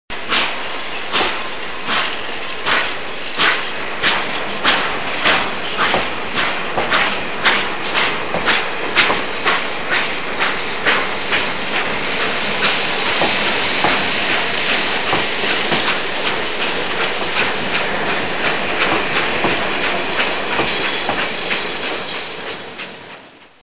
Partenza treno a vapore
Treno a vapore in movimento. Suono degli sbuffi di vapore, degli stantuffi, delle ruote, e carrozze.
treno_vapore_partenza.mp3